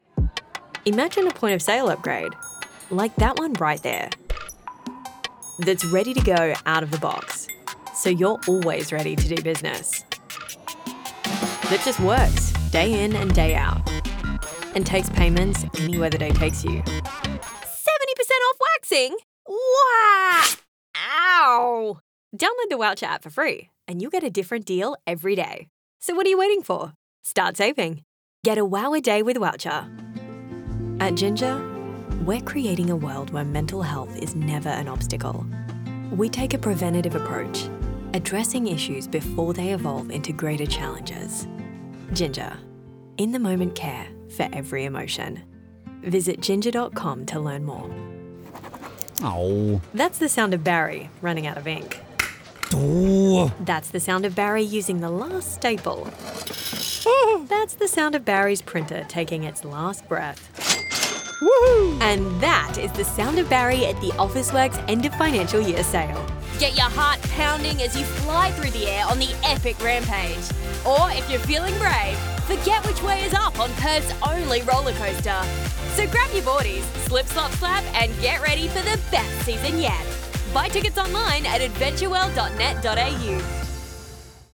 Commercial Demo - Australian
Vocal qualities: Conversational, believable, friendly, warm, upbeat, cool, natural, engaging, relatable, confident, professional.